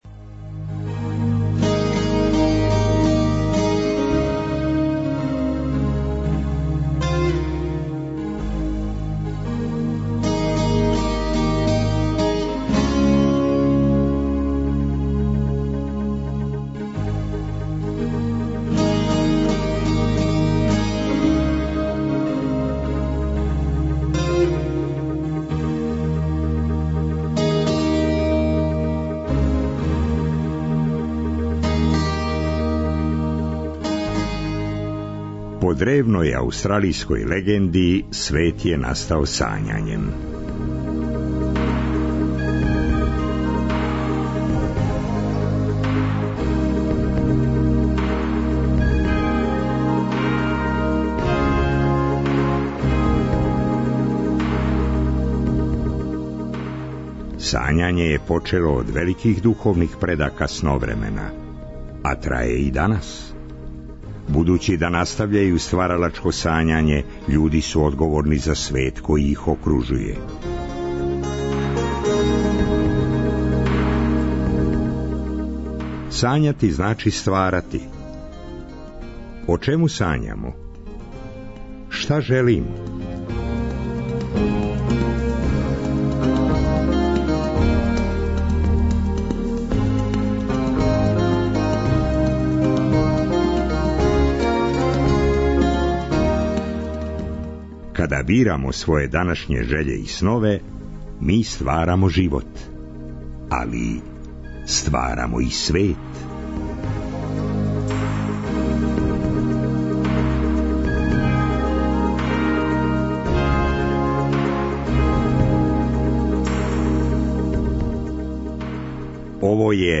За трећи и четврти сат емисије припремили смо одломке из неких на српски преведених дела Шандора Мараиа. И наравно, обиље добре музике!